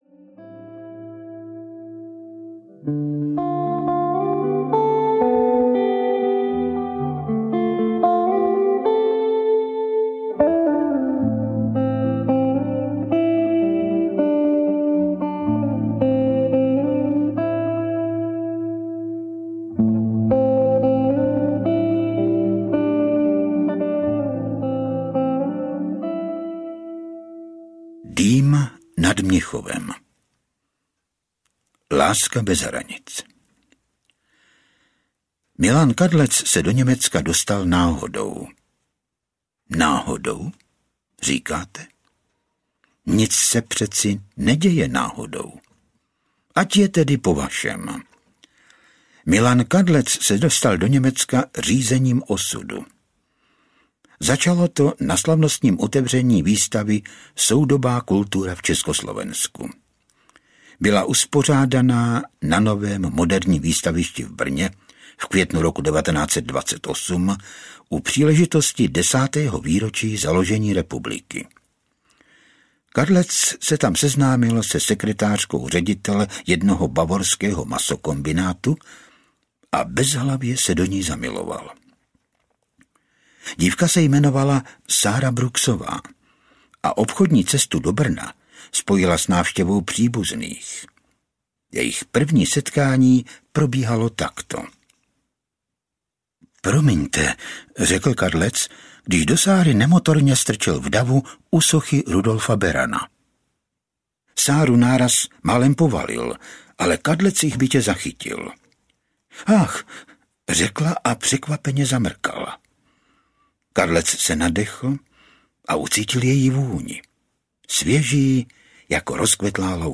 Mnichov, Smrt a Sbohem audiokniha
Ukázka z knihy